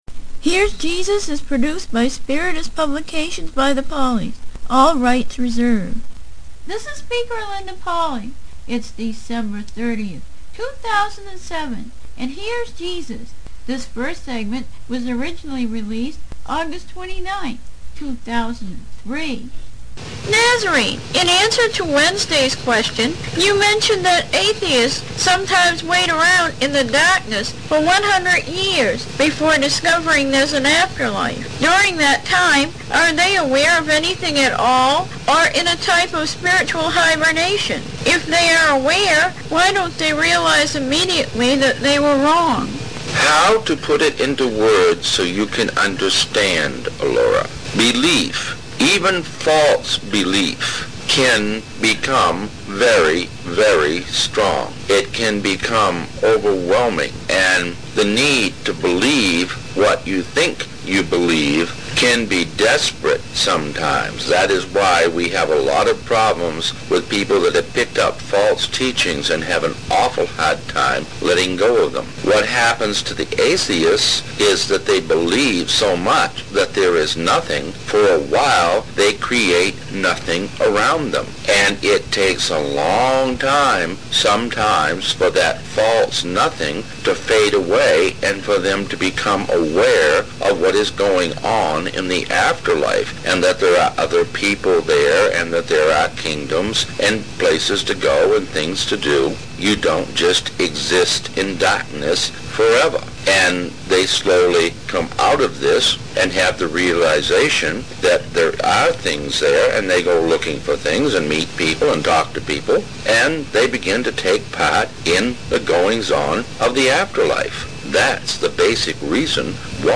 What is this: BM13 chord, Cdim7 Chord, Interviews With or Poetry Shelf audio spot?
Interviews With